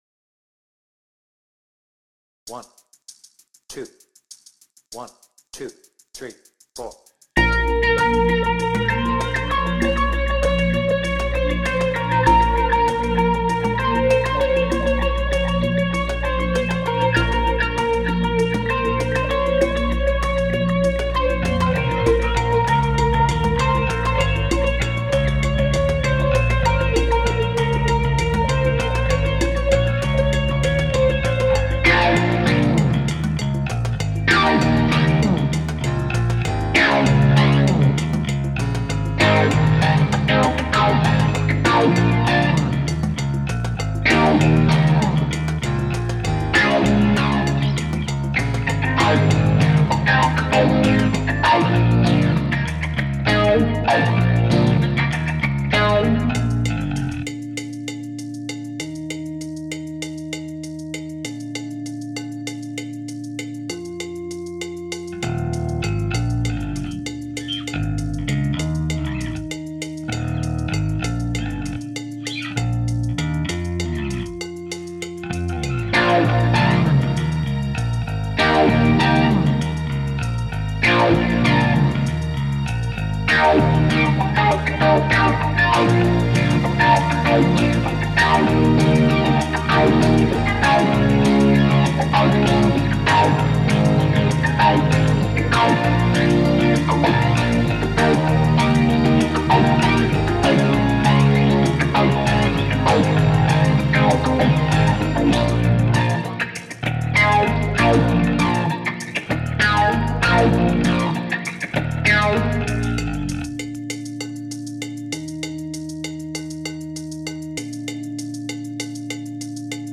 BPM : 98
Without vocals